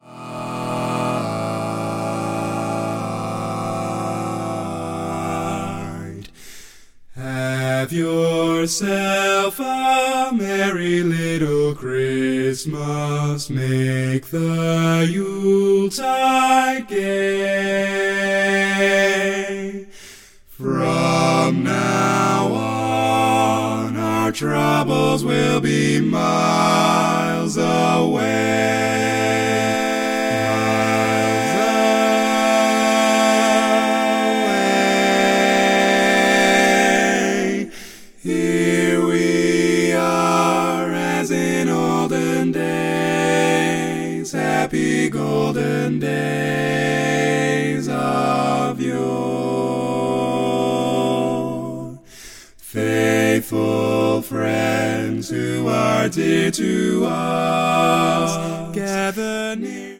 Full mix only
Category: Male